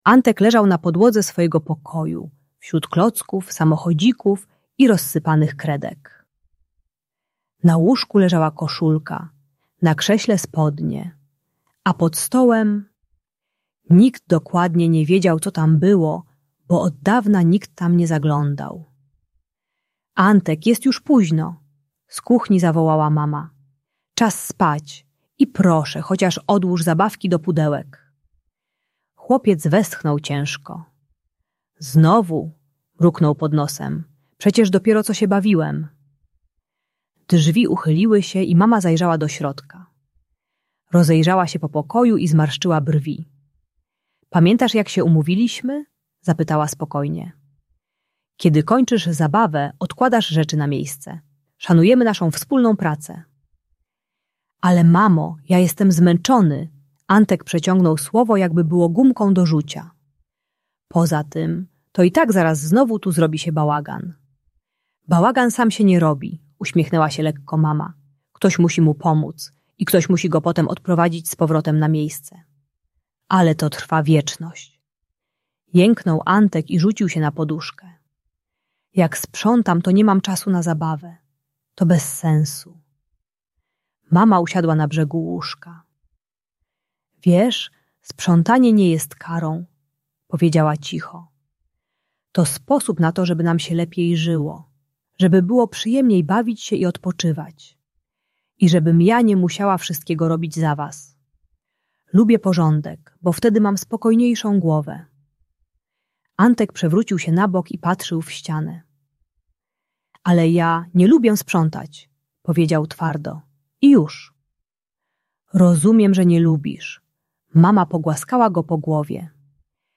Antek i Podwodny Zegar Sprzątania - Emocje rodzica | Audiobajka